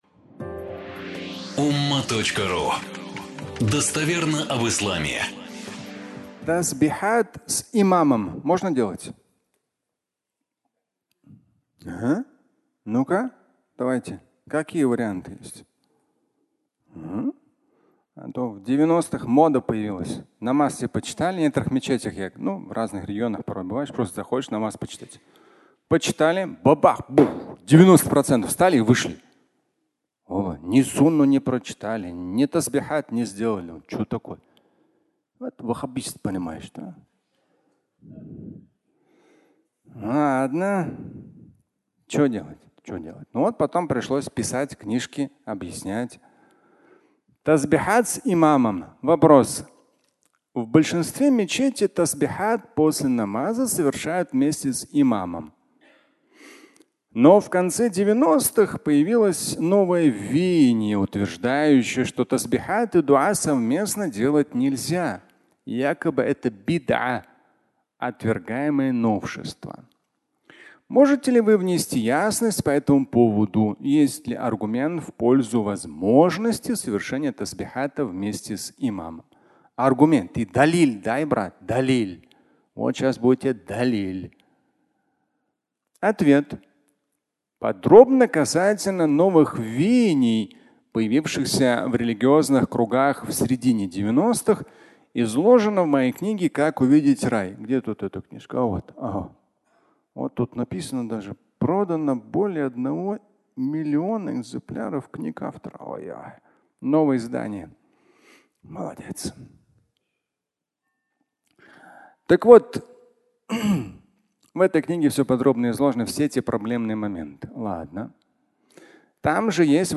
Пятничная проповедь